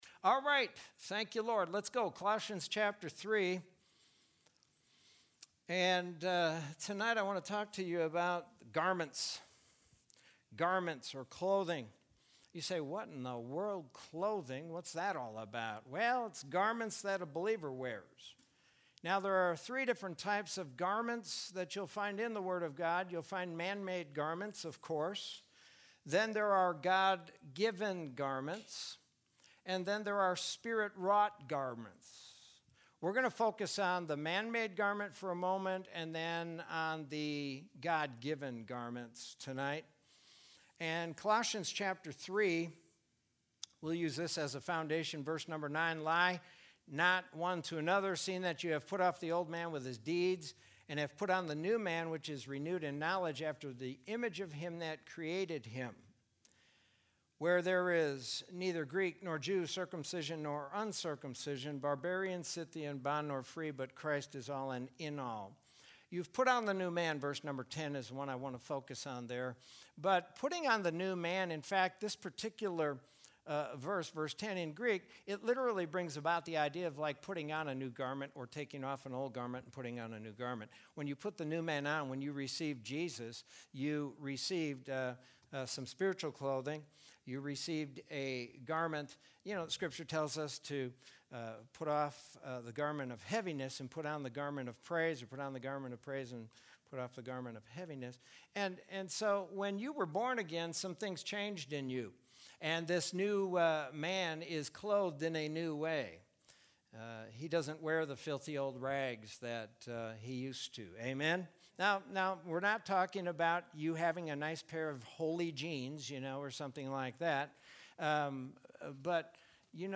Sermon from Wednesday, May 20, 2020.